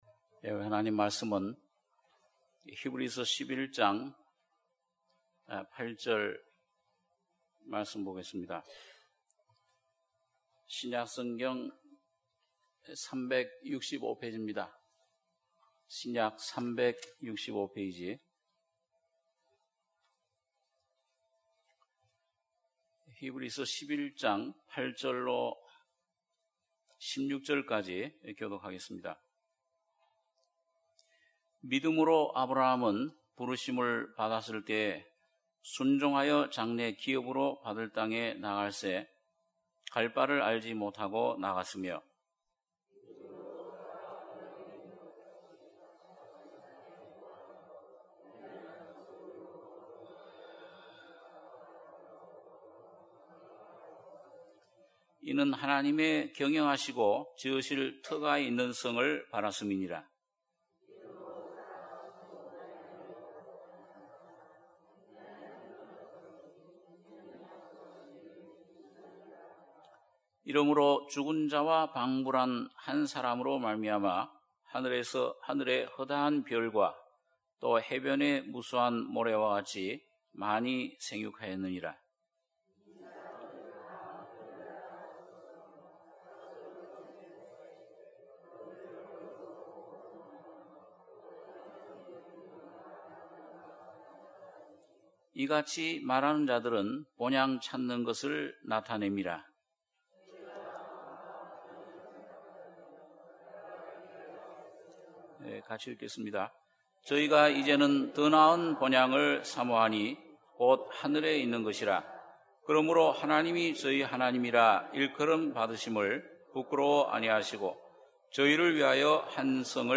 주일예배 - 히브리서 11장 8절-16절